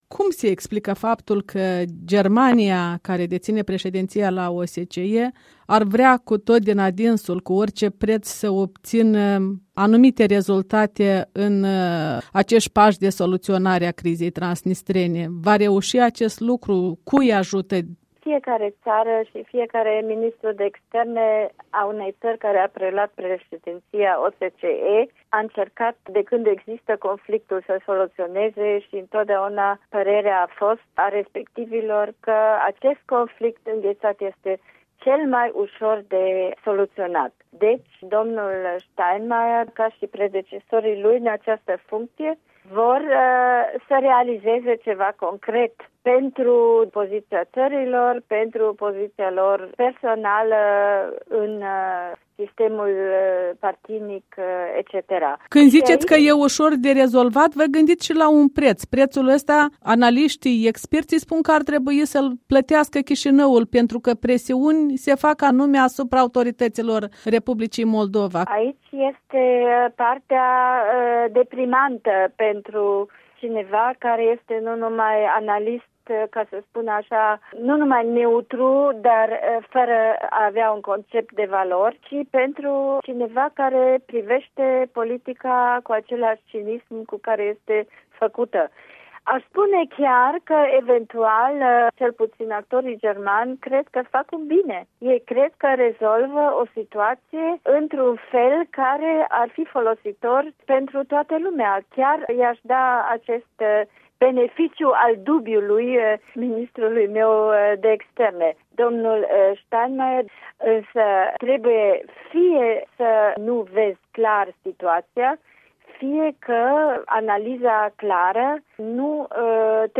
Un interviu cu analista și experta în probleme moldovene de la Berlin.